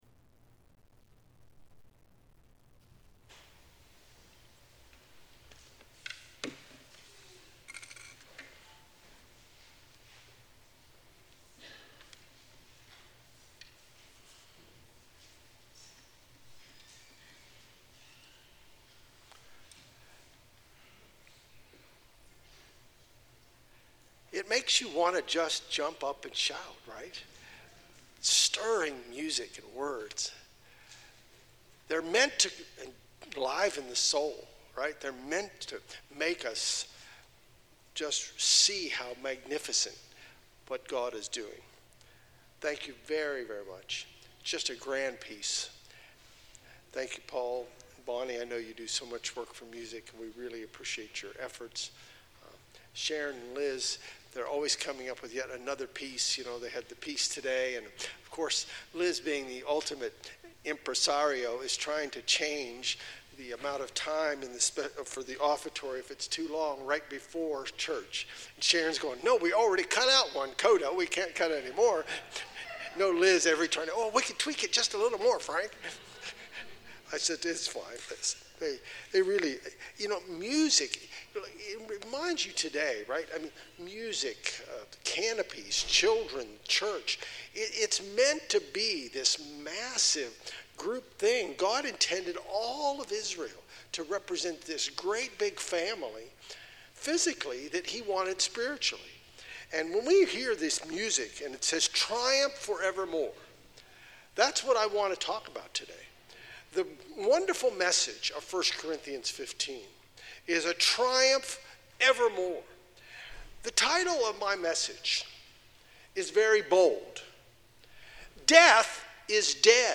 Given on the Day of Trumpets, this message proclaims the greatness of God's triumph through Christ Jesus. Through Jesus' life, death, and resurrection, we can be assured that those who are dead are merely asleep - and that the triumph of Christ risen is that death is dead.